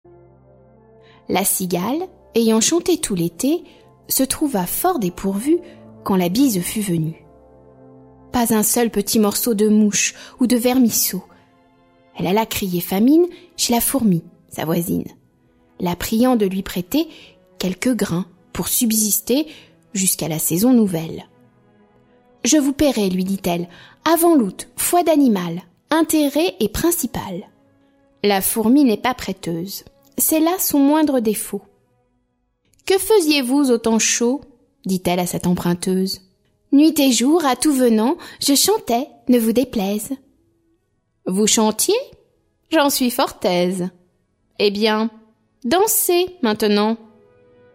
Diffusion distribution ebook et livre audio - Catalogue livres numériques
Musique : Edouard Grieg (Peer Gynt)